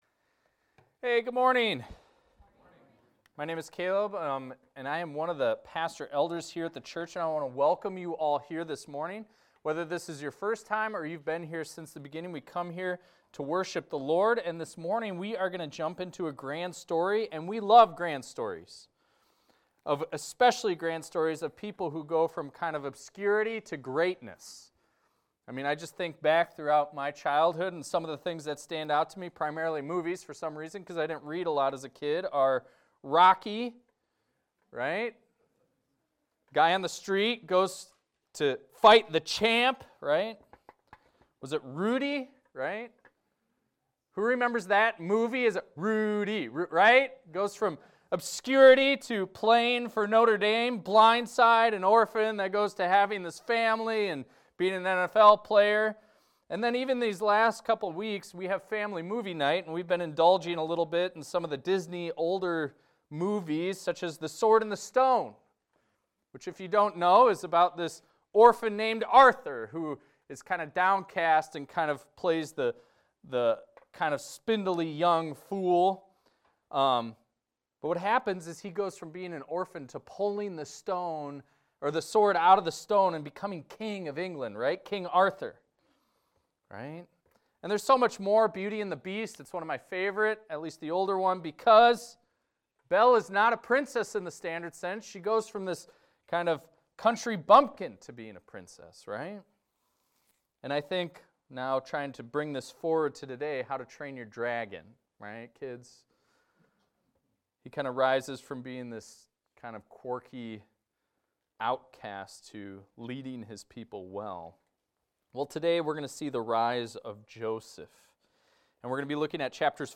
This is a recording of a sermon titled, "Deciphering Dreams."